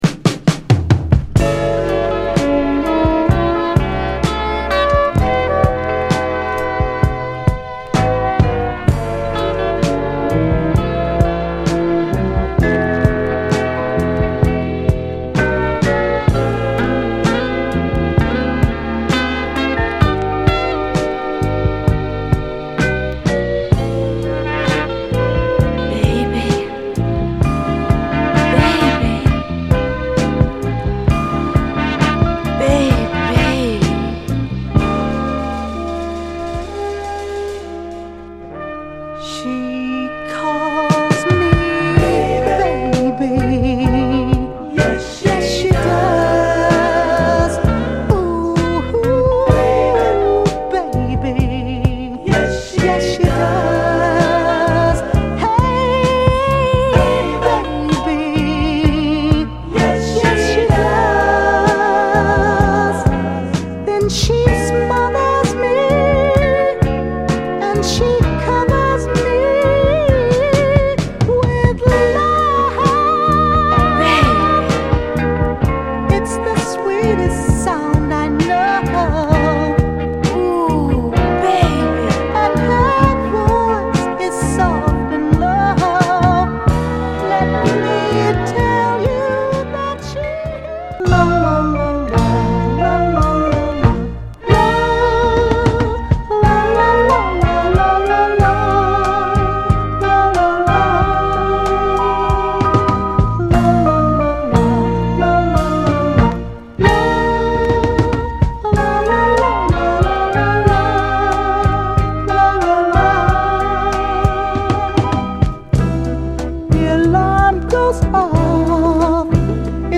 盤はいくつか細かいスレ箇所ありますが、グロスがありプレイ良好です。
※試聴音源は実際にお送りする商品から録音したものです※